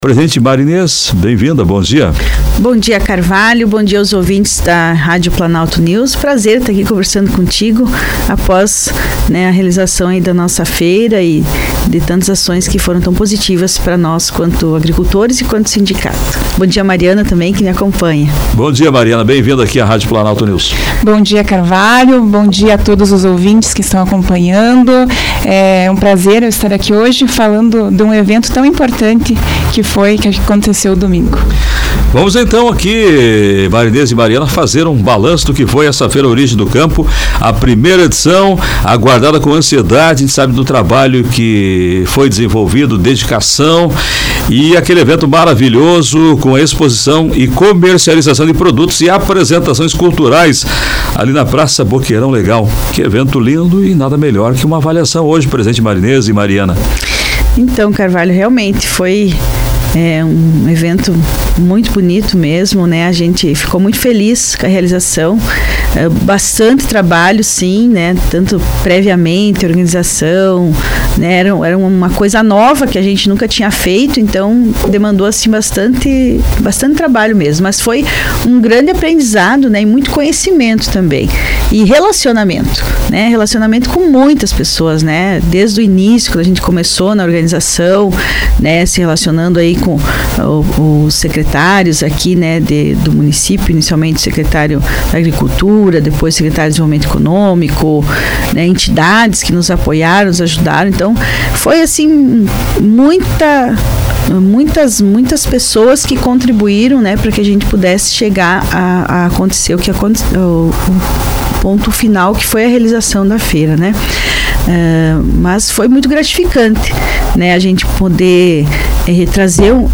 Entrevista: saldo positivo na 1ª Feira Origem do Campo